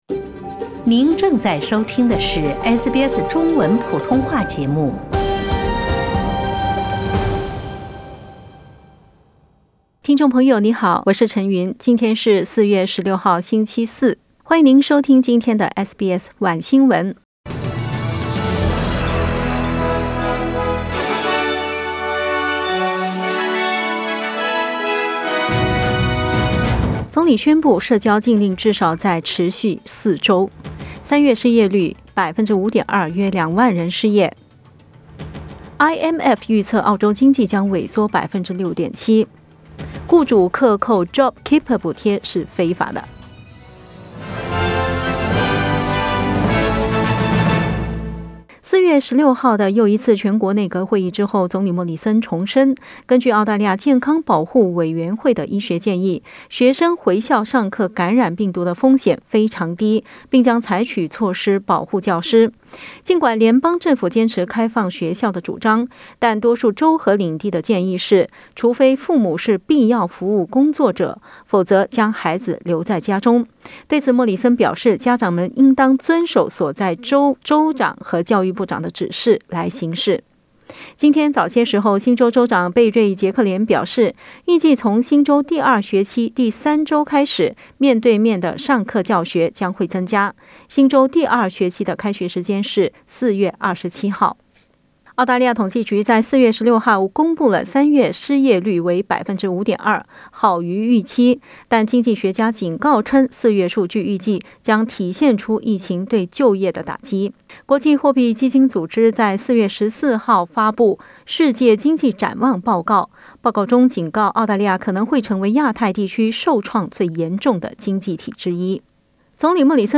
SBS晚新闻（4月16日）